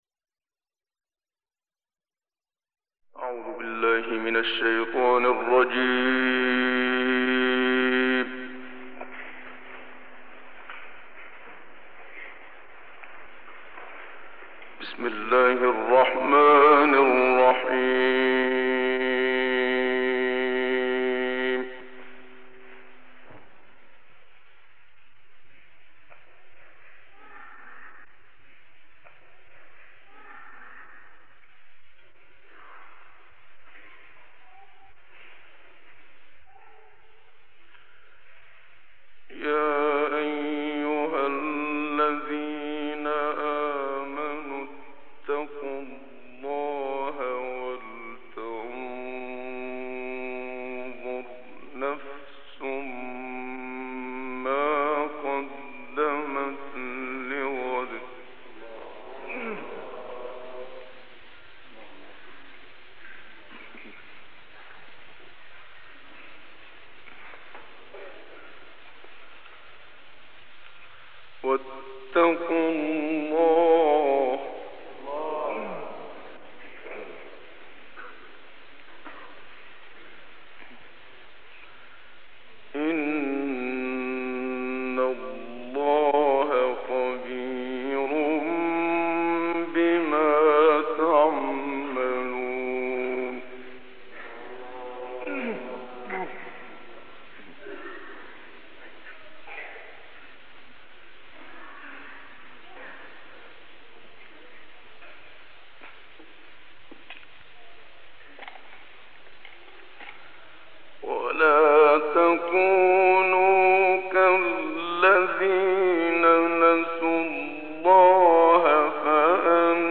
تصویری از مرحوم استاد محمد صدیق منشاوی، قاری به نام مصری همراه با تلاوت ماندگار وی از سوره‌های حشر، طارق، فجر، حمد و بقره ارائه می‌شود.